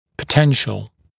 [pə’tenʃl][пэ’тэншл]потенциальный, скрытый, возможный